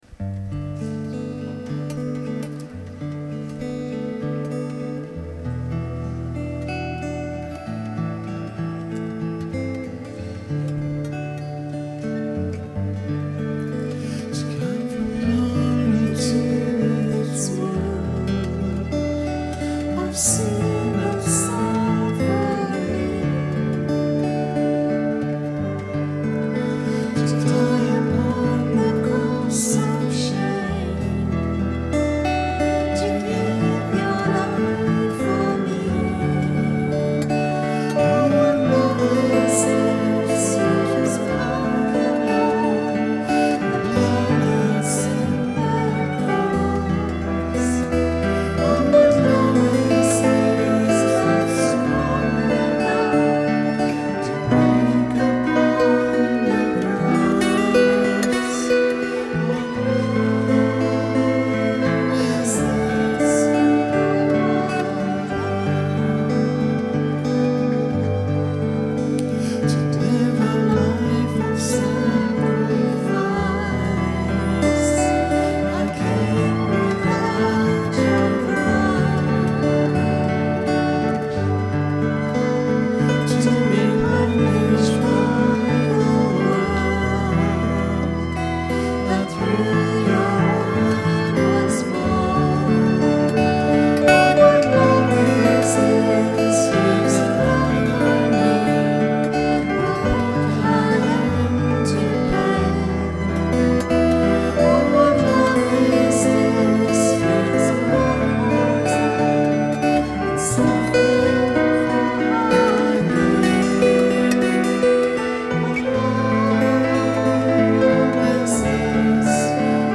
A song I wrote for Lent, recorded at our Sunday service today: